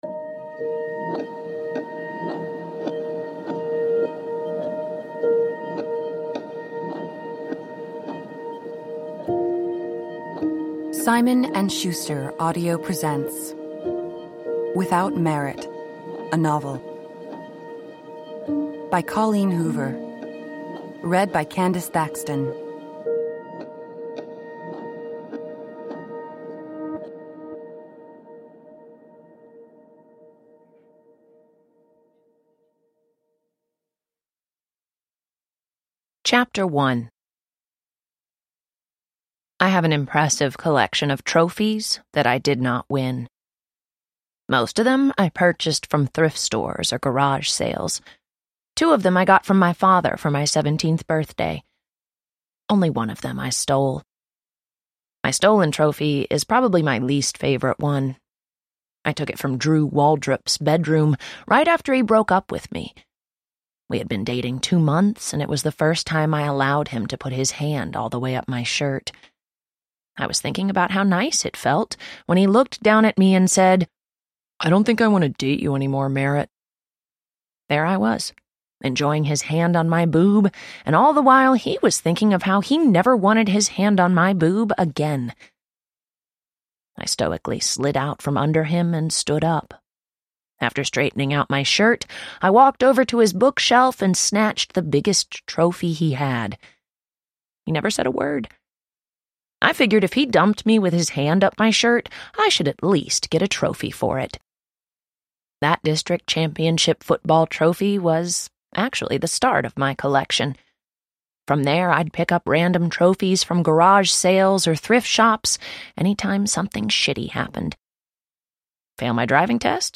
Without Merit – Ljudbok